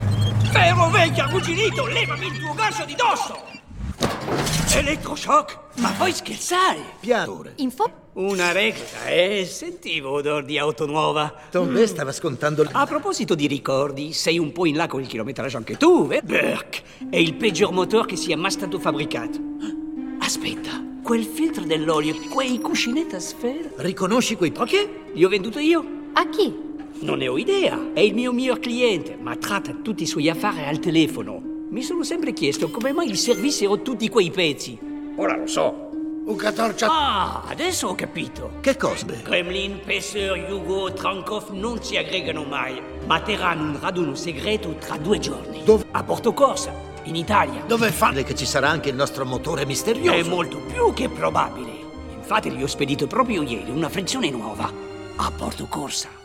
nel film d'animazione "Cars 2", in cui doppia Tomber.